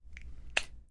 加压门开启
描述：一个加压的门在0.3秒内打开，通过混合几个声音。
标签： 气闸舱 开放 金属 开放 开放
声道立体声